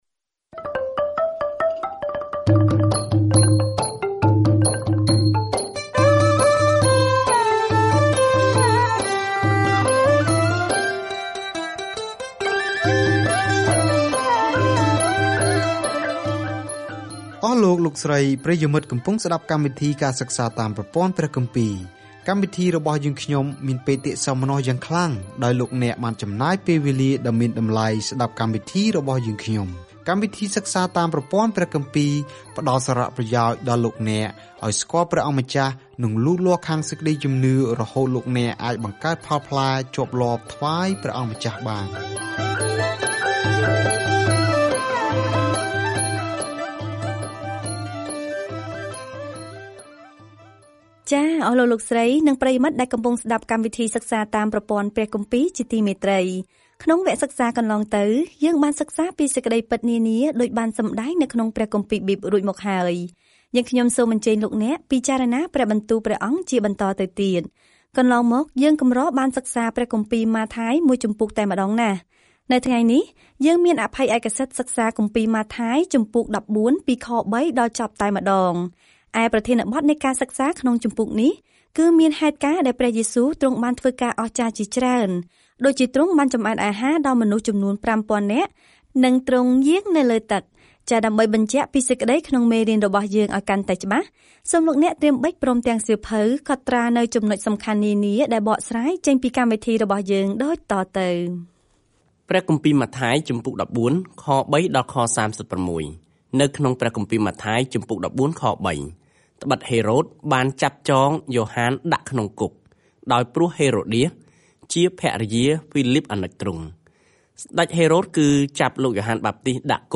ម៉ាថាយបង្ហាញដល់អ្នកអានសាសន៍យូដានូវដំណឹងល្អថា ព្រះយេស៊ូវគឺជាព្រះមេស្ស៊ីរបស់ពួកគេ ដោយបង្ហាញពីរបៀបដែលជីវិត និងកិច្ចបម្រើរបស់ទ្រង់បានបំពេញតាមទំនាយក្នុងព្រះគម្ពីរសញ្ញាចាស់ ។ ការធ្វើដំណើរប្រចាំថ្ងៃតាមម៉ាថាយ ពេលអ្នកស្តាប់ការសិក្សាជាសំឡេង ហើយអានខគម្ពីរដែលជ្រើសរើសពីព្រះបន្ទូលរបស់ព្រះ។